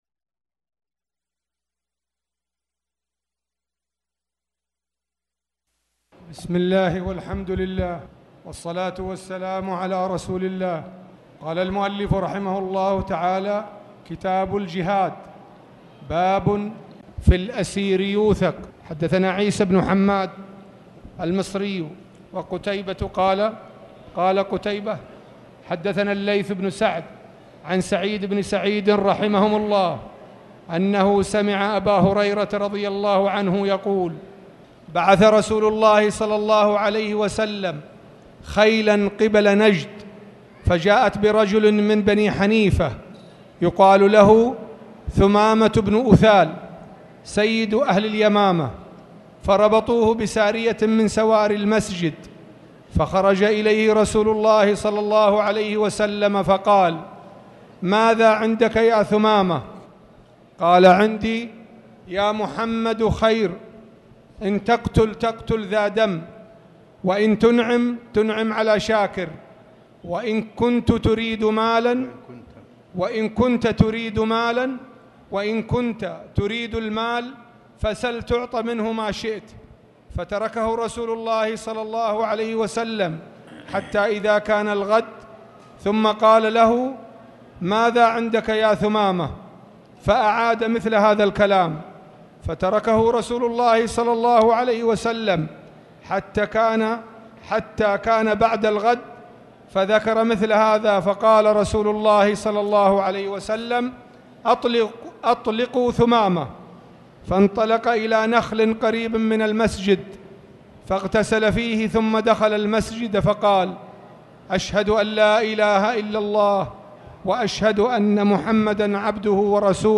تاريخ النشر ٢٩ صفر ١٤٣٩ هـ المكان: المسجد الحرام الشيخ